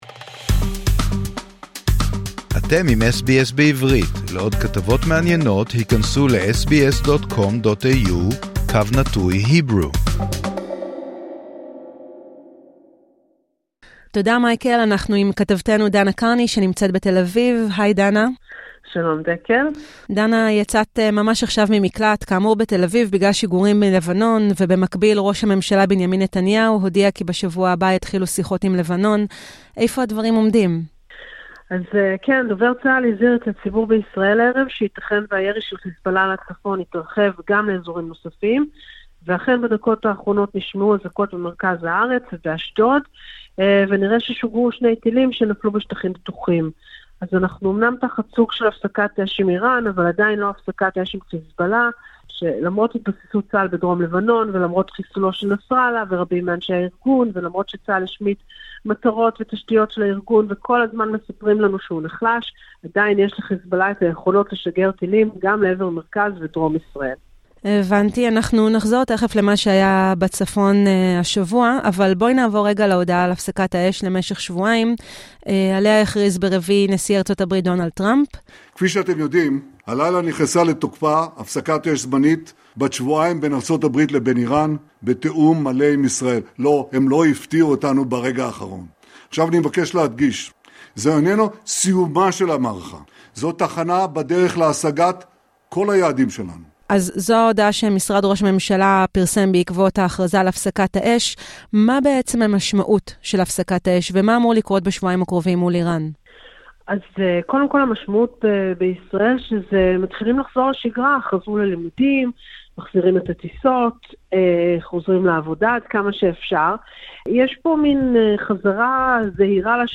בעדכון חדשות מהשבוע החולף